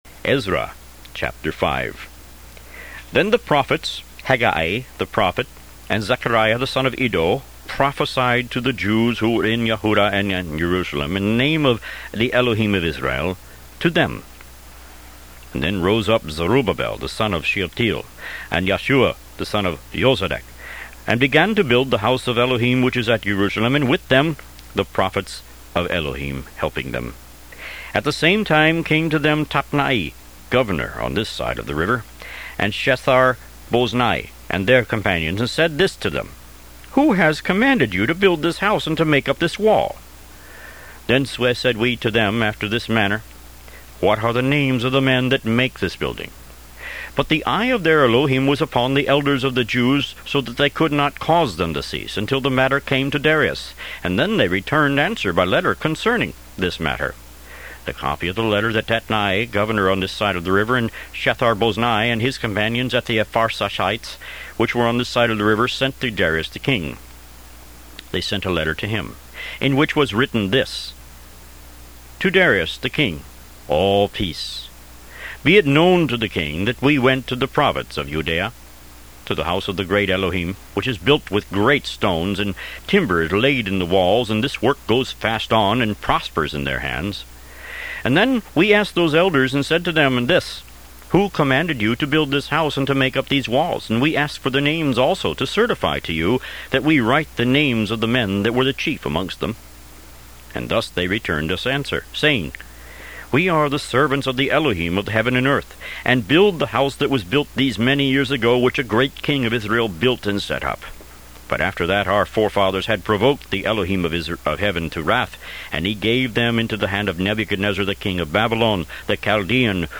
Root > BOOKS > Biblical (Books) > Audio Bibles > Tanakh - Jewish Bible - Audiobook > 15 Ezra